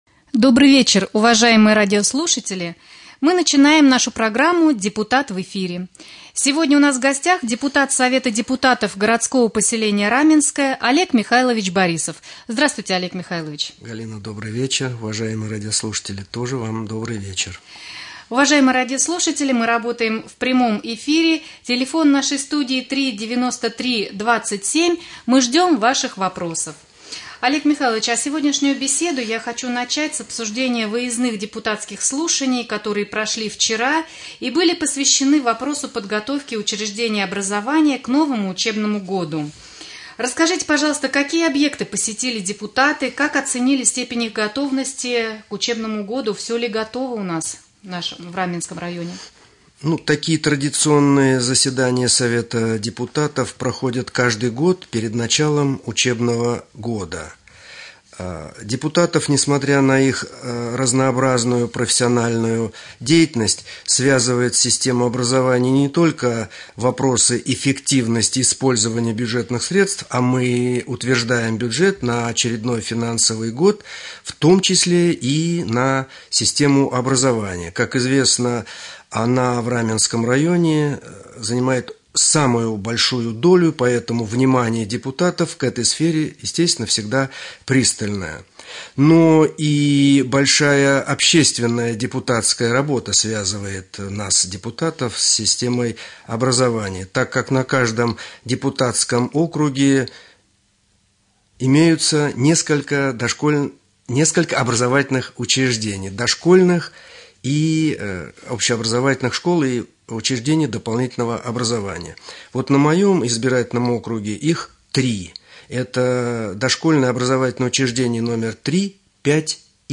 Прямой эфир.Гость студии депутат городского поселения Раменское Олег Михайлович Борисов.